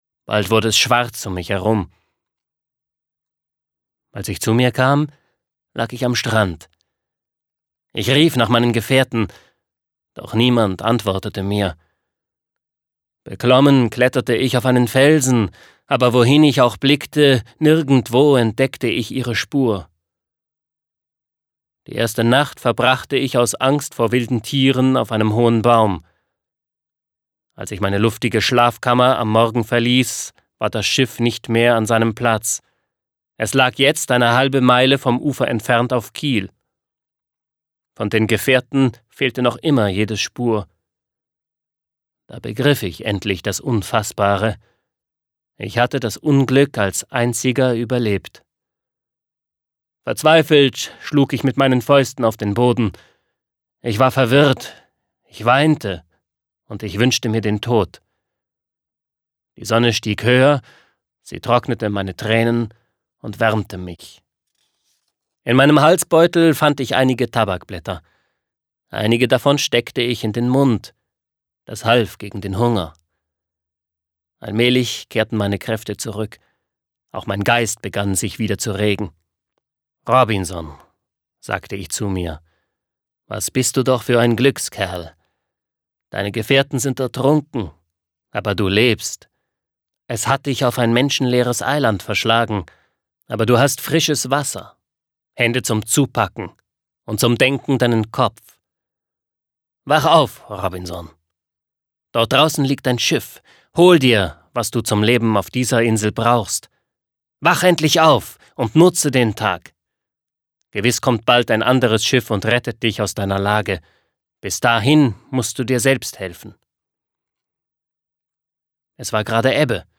Schlagworte Abenteuer • Hörbuch; Lesung für Kinder/Jugendliche • Kinder/Jugendliche: Action- & Abenteuergeschichten • Kinder/Jugendliche: Action- & Abenteuergeschichten • Kinder/Jugendliche: Gegenwartsliteratur • Klassiker • Spannung